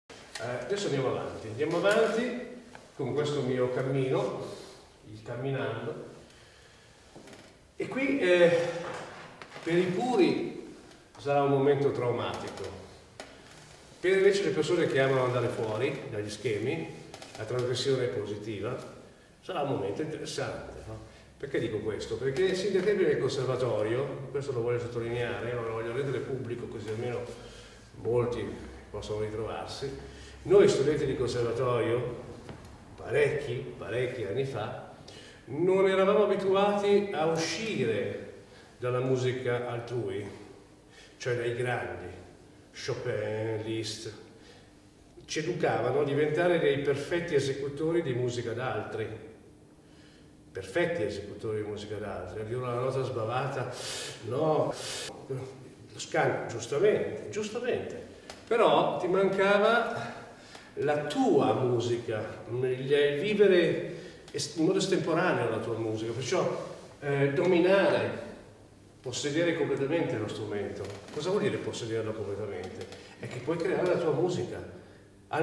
Tra Classica e Jazz
IV OTTOBRE MUSICALE A PALAZZO VALPERGA
pianista